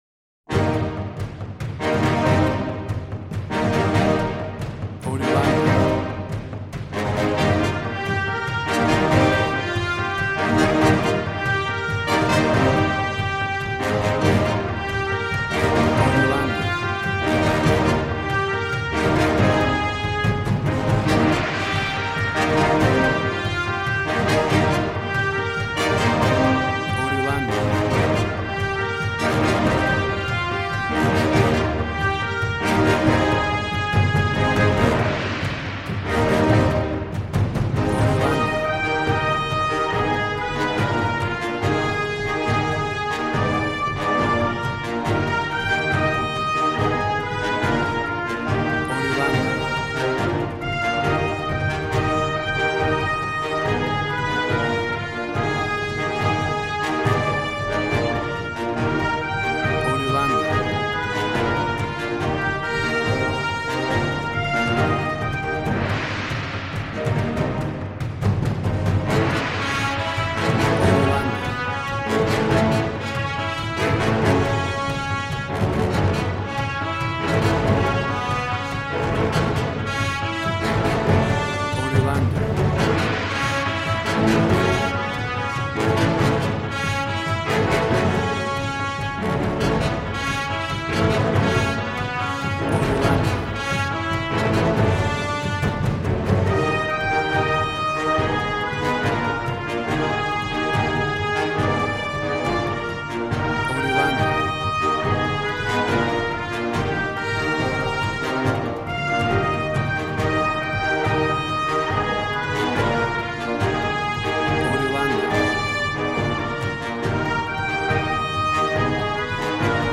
Action and Fantasy music for an epic dramatic world!
Tempo (BPM): 70